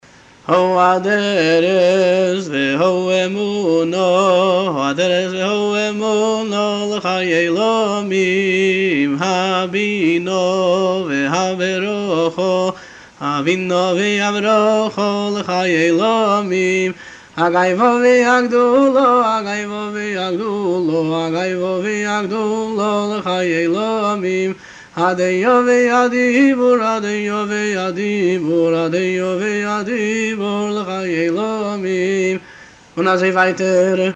לחן חסידי